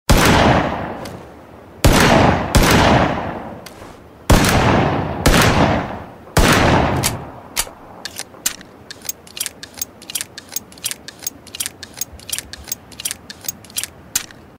zvuk-vystrela-revolvera-i-zaryadka
• Категория: Перезарядка оружия
• Качество: Высокое